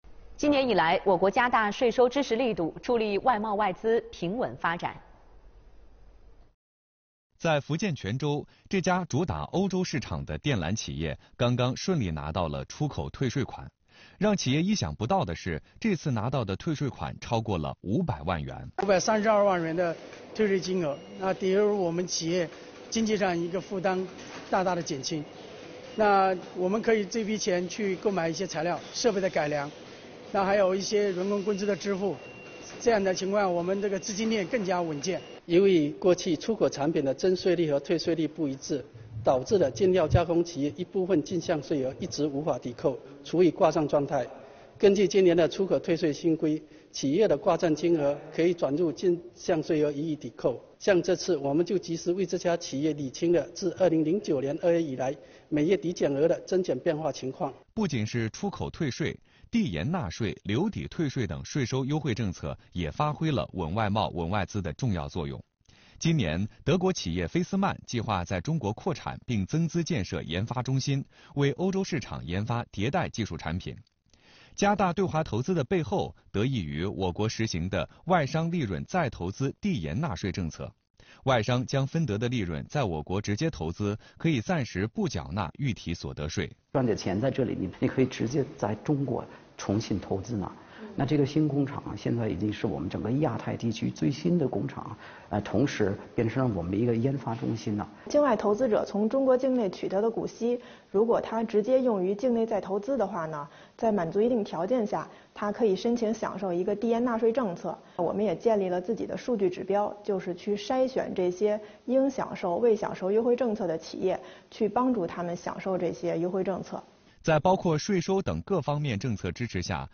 央视《朝闻天下》《新闻直播间》等栏目就此做了相关报道。
视频来源：央视《新闻直播间》